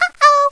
SFX回答错误啊哦的提示音效下载
SFX音效